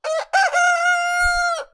Add rooster sound (CC0)
sounds_rooster.ogg